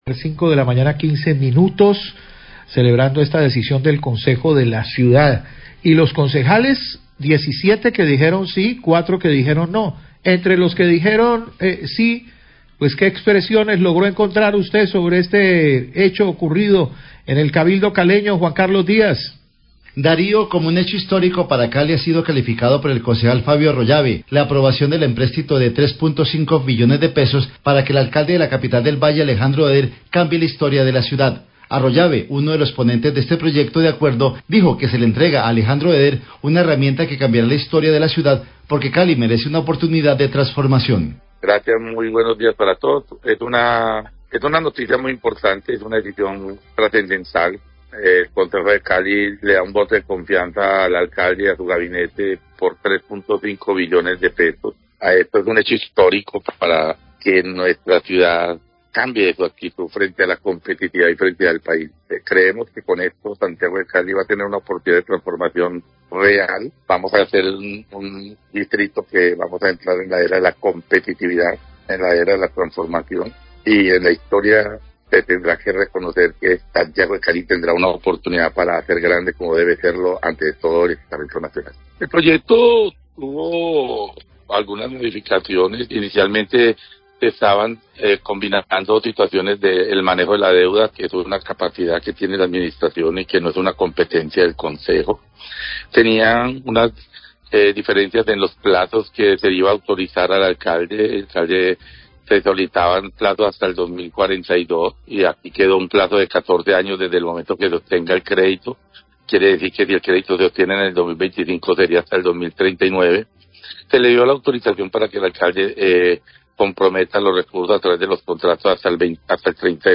Concejales caleños y director de Hacienda se refieren a aprobación de empréstito,
Radio
Concejales de Cali que votaron positivamente el proyecto de acuerdo para el empréstito presentado por la Alcaldía de Cali. Declaraciones del concejal Fabio Arroyave quien afirma que se le entrega al alcalde de Cali, Alejandro Eder, un voto de confianza para cambiar la ciudad.